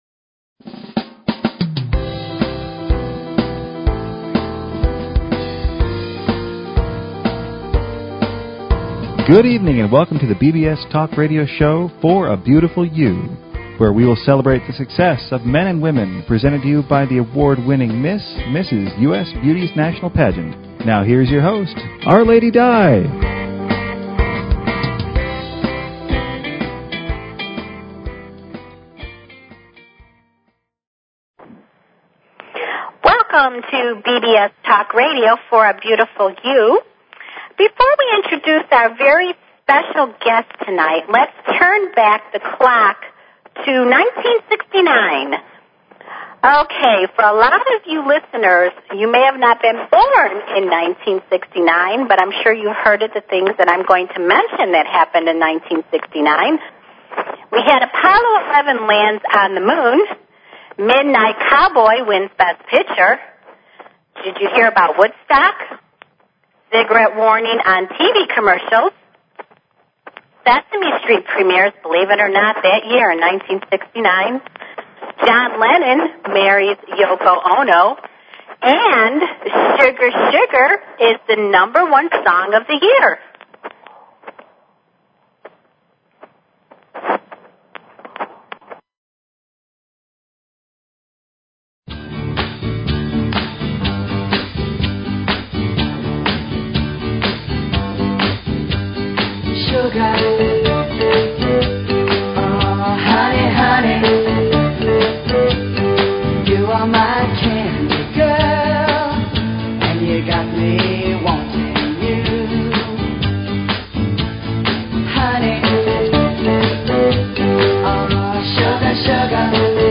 Talk Show Episode, Audio Podcast, For_A_Beautiful_You and Courtesy of BBS Radio on , show guests , about , categorized as